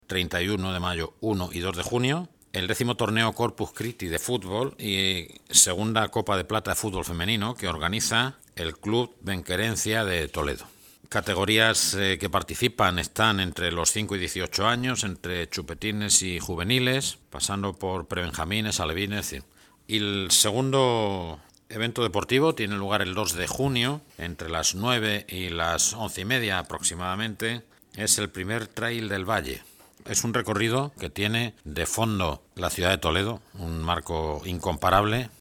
Presentación de tres eventos deportivos en Toledo